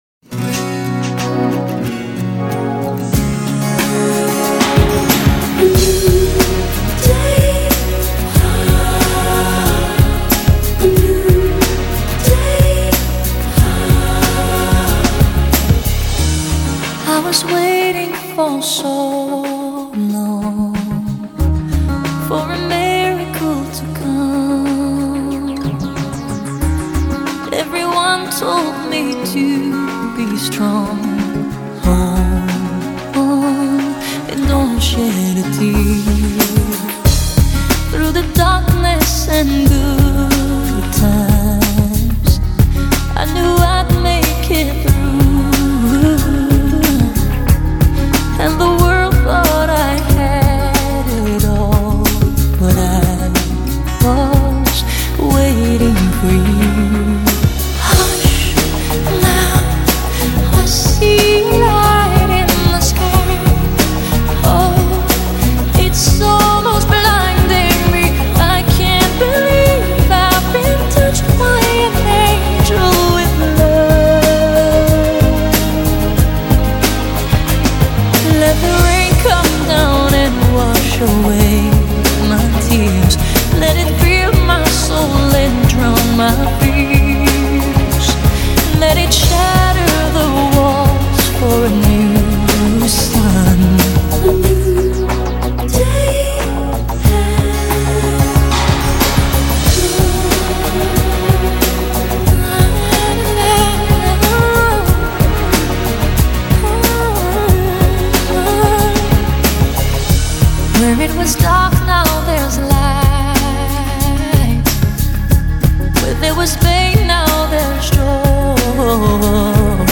而慢版本是她声线的最好体现。
在细腻悠扬、情感丰沛的歌声中，感受到旺盛的生命能量